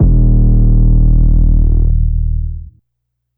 808 [DRIVE THE BOAT].wav